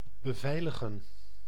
Ääntäminen
IPA: [a.bʁi.te]